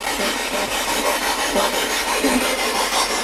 The SB11 is very similar to the SB7, except that it will scan two channels simultaneously. I was using both channels at a 150 millisecond sweep rate (around 7 1/2 audio frequencies per second). I scanned forward on one channel, and reverse on the other. The file ends suddenly because at that point I spoke.
Young girl makes statement in Lena and Ina's bedroom I hear a young female stating, "He murdered us."